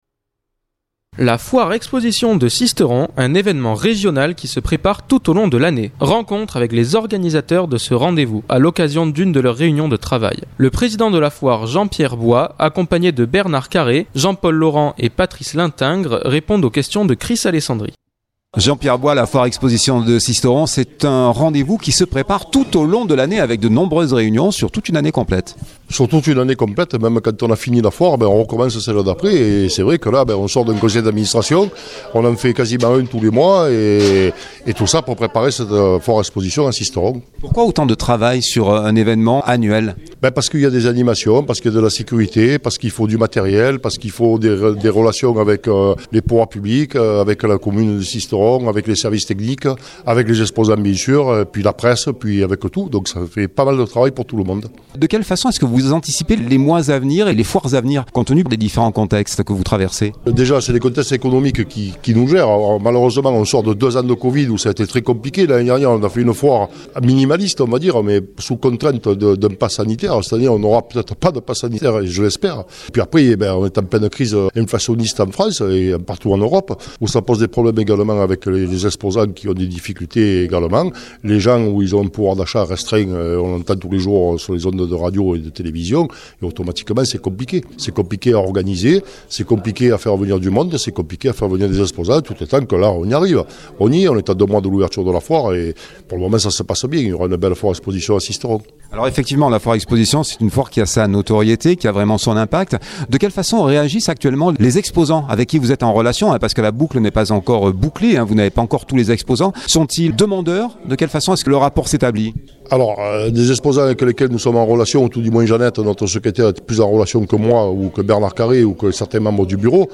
Rencontre avec les organisateurs de ce rendez-vous, à l’occasion d’une de leurs réunions de travail.